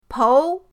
pou2.mp3